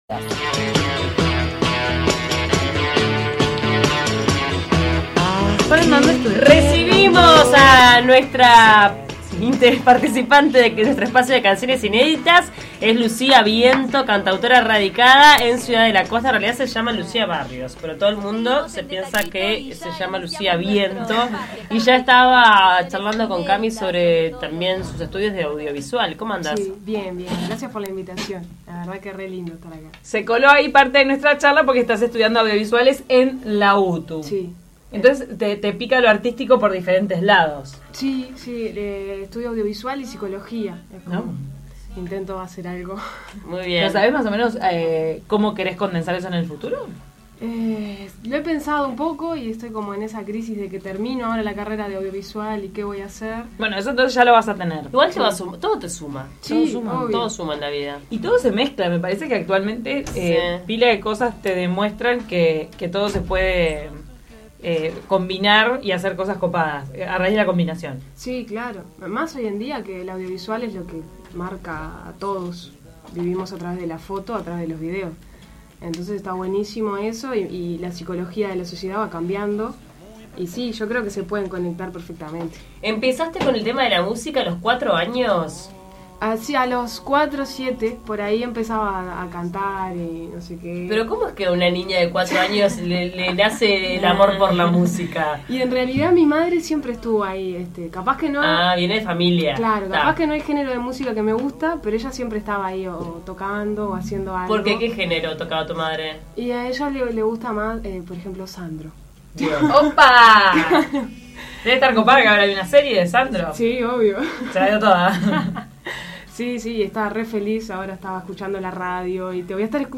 presentó un tema inédito sin nombre en De taquito, que trata sobre tomarse un tiempo para las cosas y no apurarse.